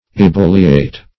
Ebulliate \E*bul"li*ate\